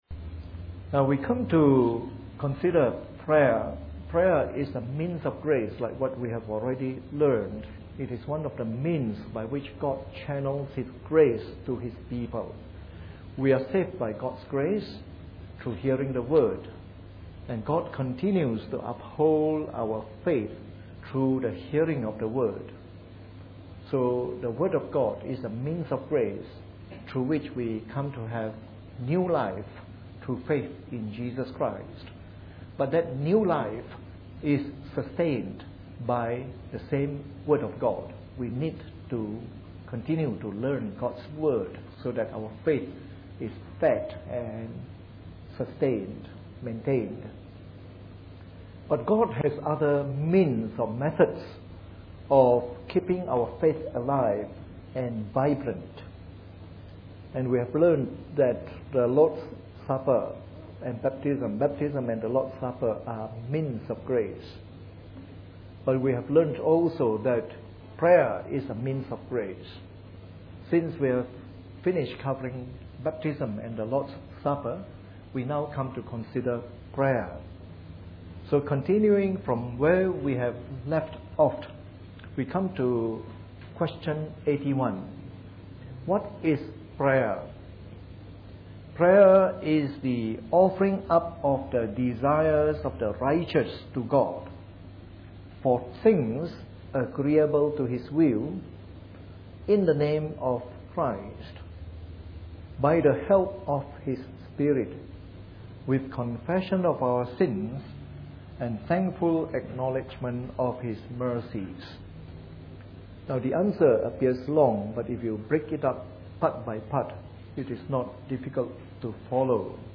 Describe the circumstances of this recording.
Preached on the 27th of July 2011 during the Bible Study from our current series on the Shorter Catechism.